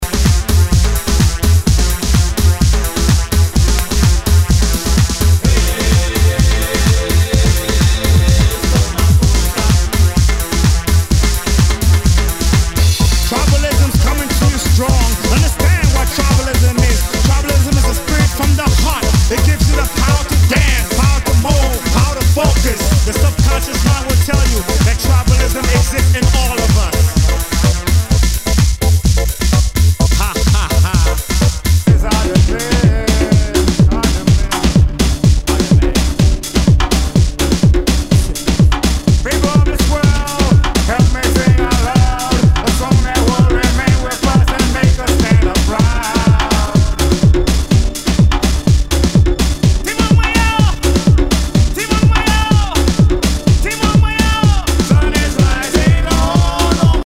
HOUSE/TECHNO/ELECTRO
ナイス！トライバル・ハウス！